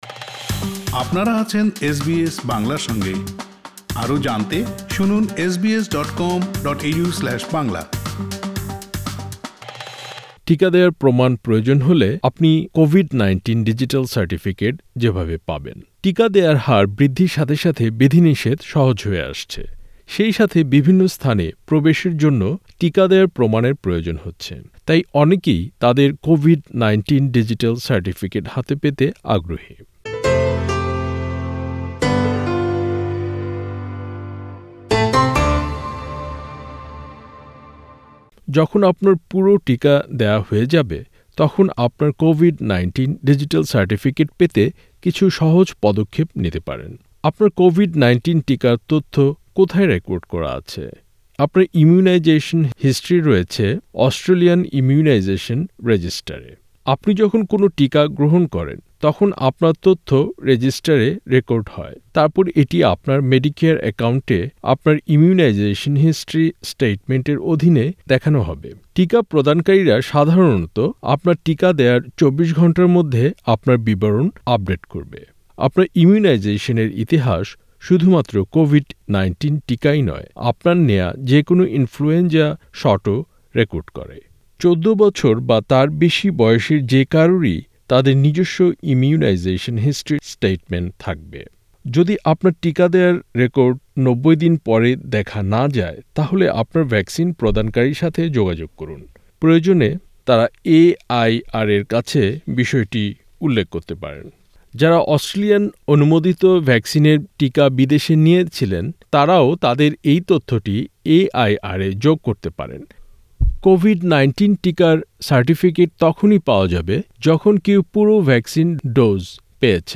টিকা দেওয়ার হার বৃদ্ধির সাথে সাথে বিধিনিষেধ সহজ হয়ে আসছে, সেই সাথে বিভিন্ন স্থানে প্রবেশের জন্য টিকা দেওয়ার প্রমাণের প্রয়োজন হচ্ছে। তাই অনেকেই তাদের কোভিড ১৯ ডিজিটাল সার্টিফিকেট হাতে পেতে আগ্রহী। প্রতিবেদনটি শুনতে উপরের অডিও-প্লেয়ারটিতে ক্লিক করুন।